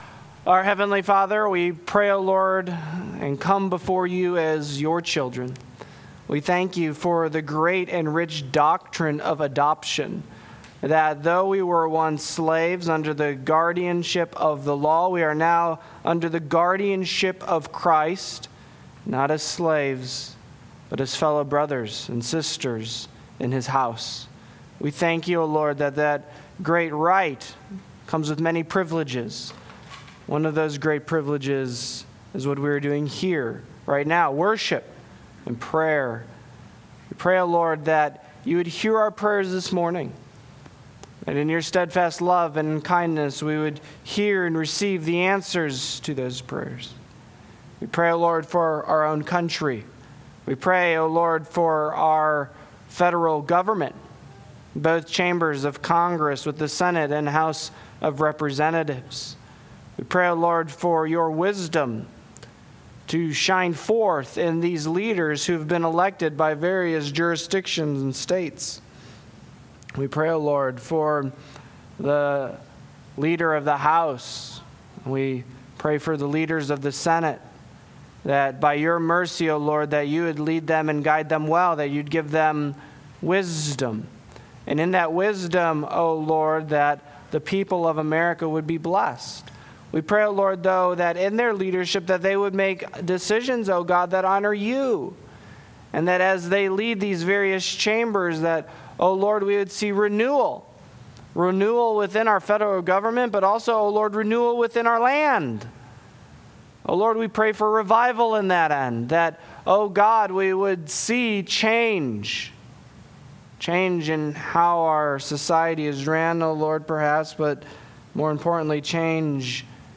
Sermons Podcast - Counted Righteous Part 2 | Free Listening on Podbean App